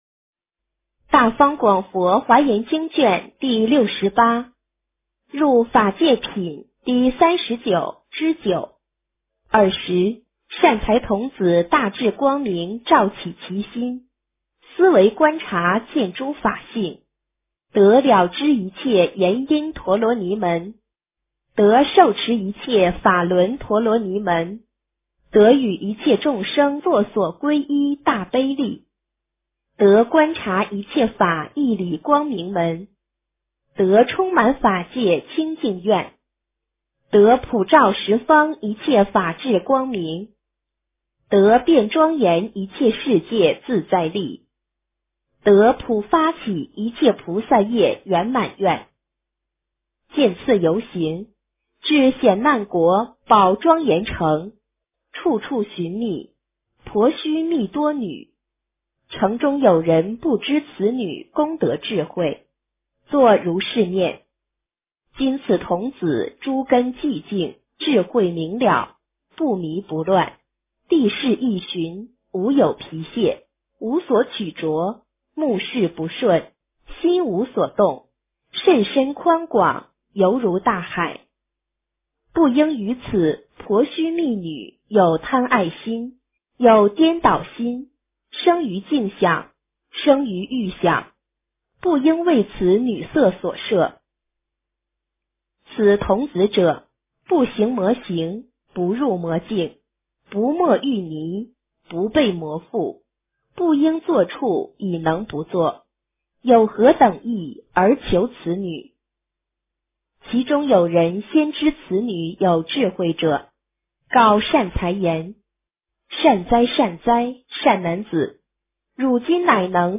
华严经68 - 诵经 - 云佛论坛